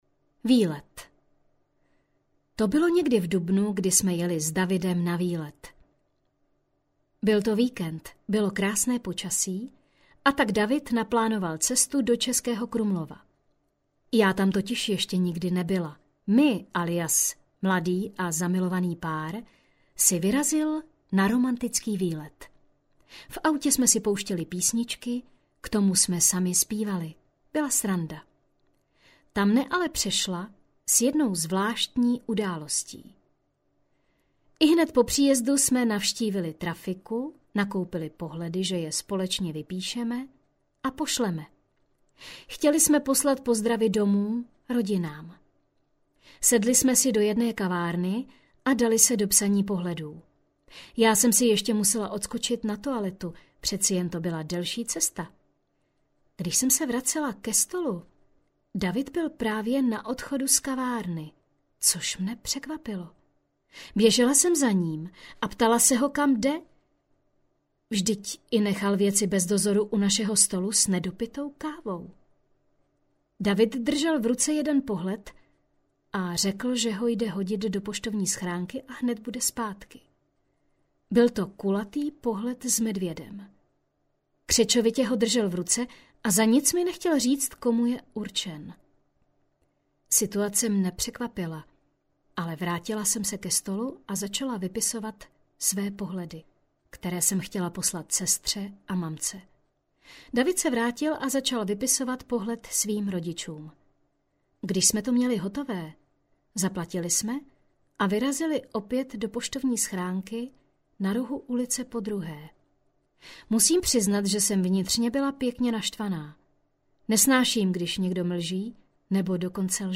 Poslechnout ukázku Audioknihy
Ukázka audioknihy Příběh jednoho vztahu.